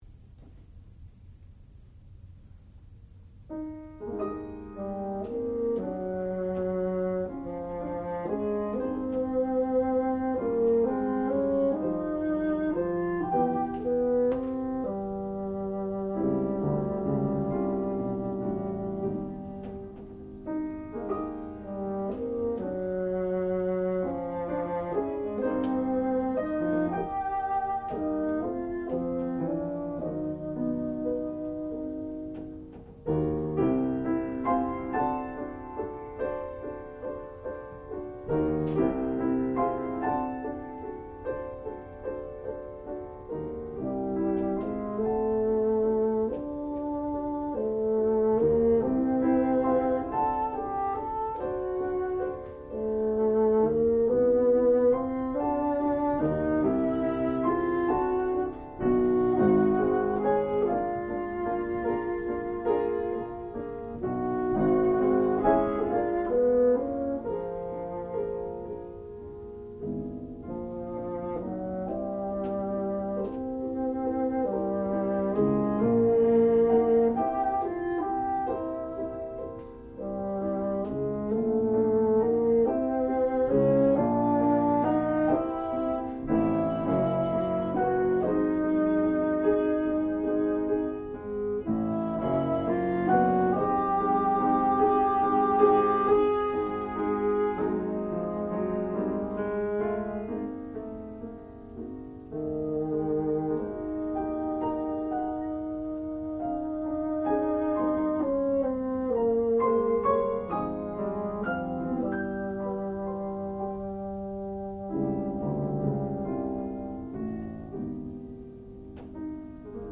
classical music
piano
bassoon
Junior Recital